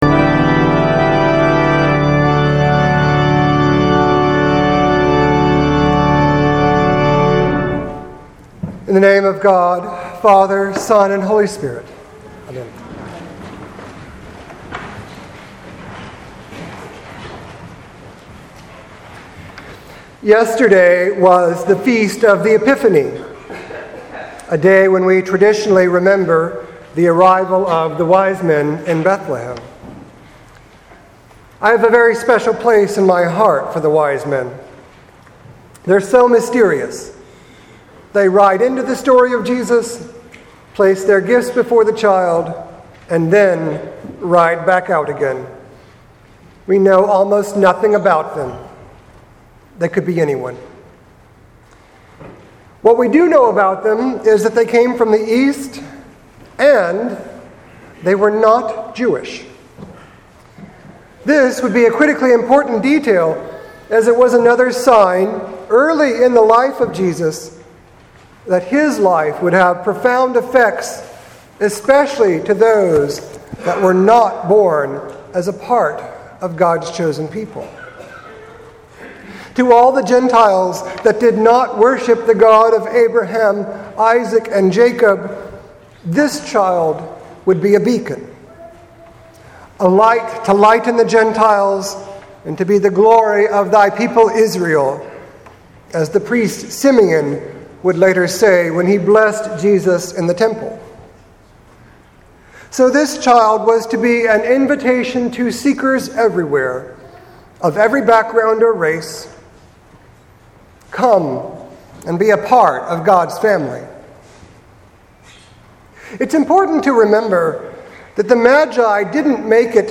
Sermon for January 7th, 2018